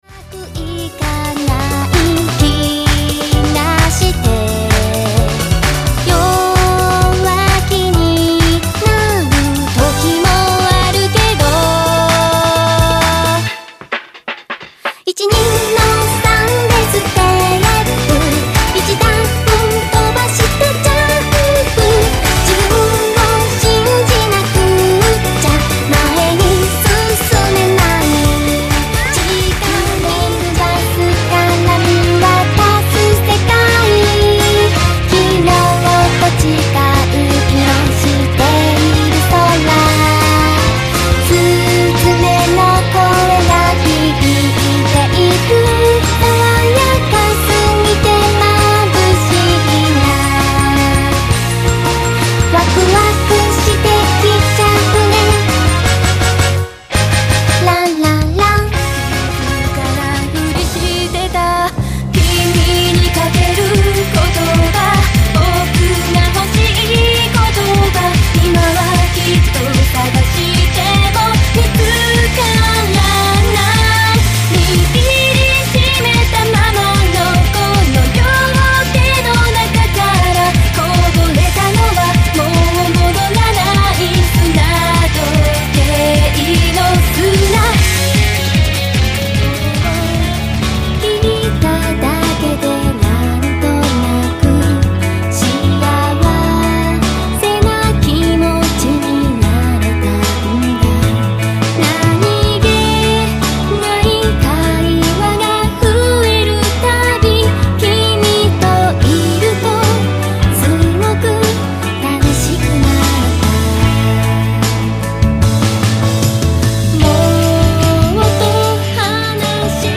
■全曲クロスフェード公開中　⇒
曲によってノイズ（音割れ）が入ることがありますが、製作者の意図によるものですのでご了承くださいませ。